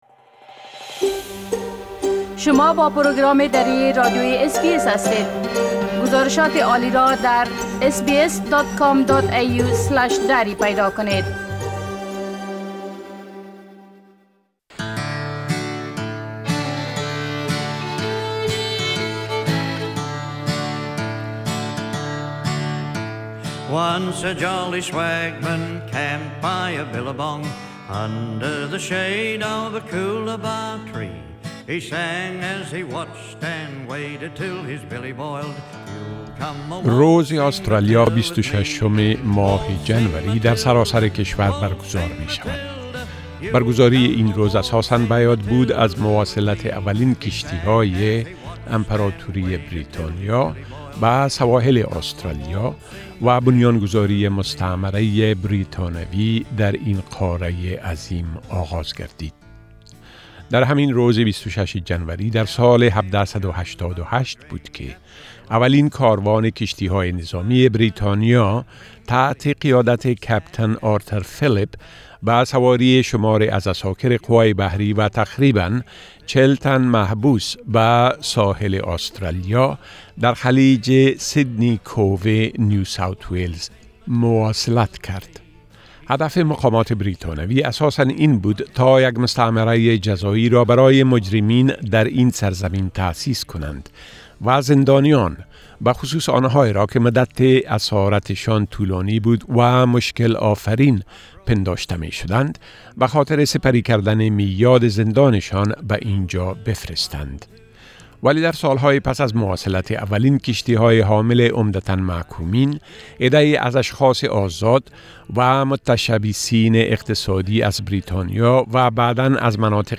این‌ها سوال‌هایی بودند که ما در آستانه روز ملی آسترالیا از تعدادی از افغان‌‌های آسترالیایی پرسیدیم.